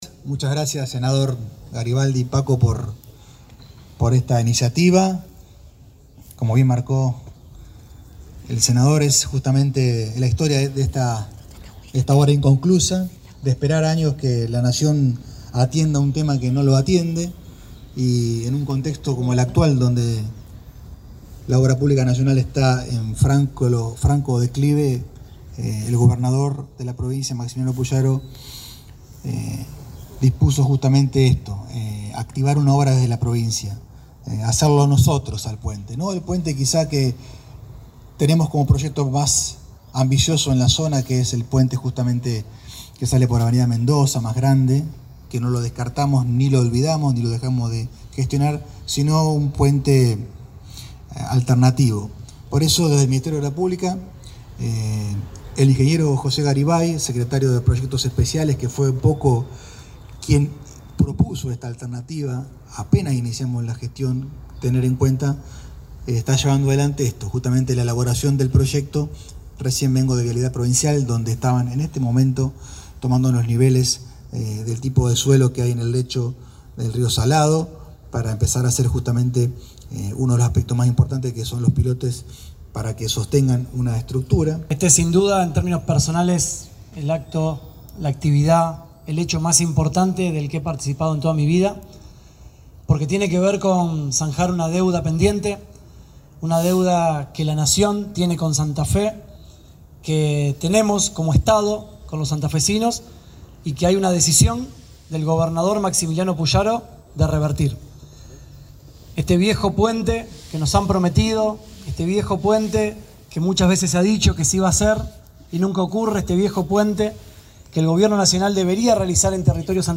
Fragmentos de las alocuciones de Enrico y Garibaldi